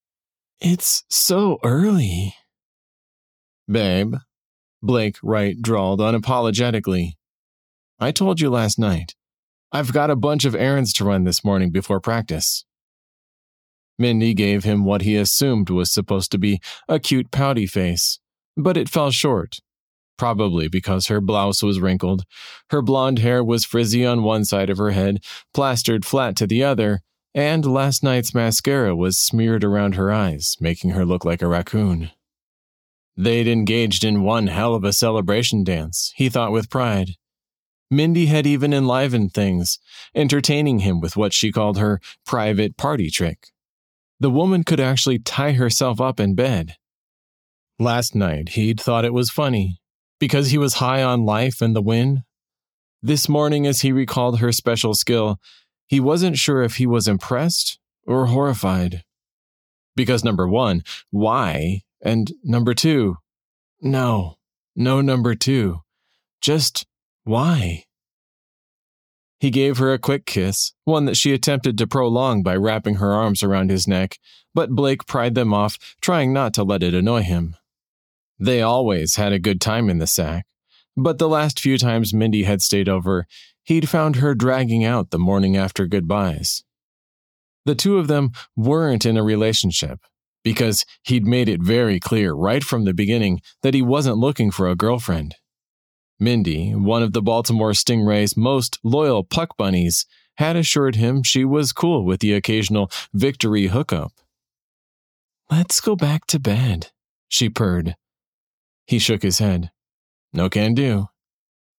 Audiobook Paperback GoodReads Bookbub
15_PD270_Restraint_MaleSample.mp3